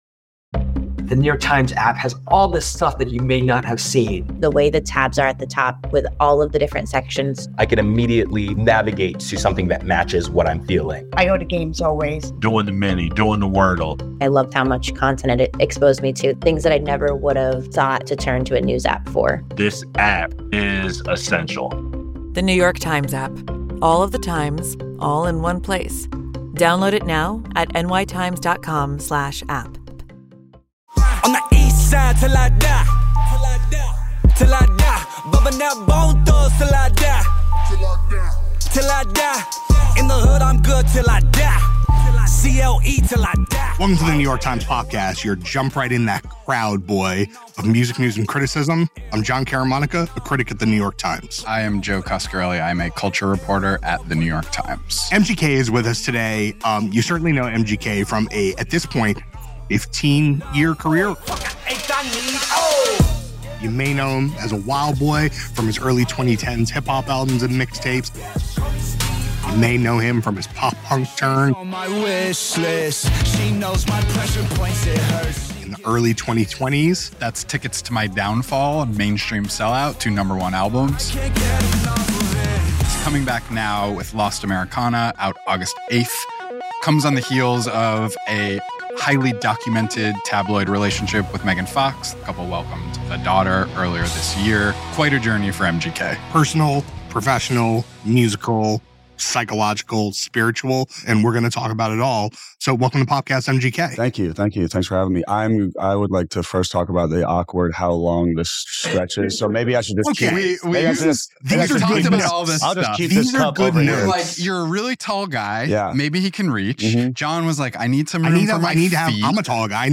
MGK Interview! On Bob Dylan, Megan Fox, Rehab & His New Pop Album